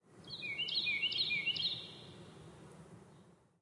家用 " RB H 家用刀 01
描述：金属刀对磨刀器的刮擦。立体声录音，用Zaxcom Deva II录音，森海塞尔MKH 30和MKH 40作为中侧，解码为AB立体声。
标签： 家用 室内 金属
声道立体声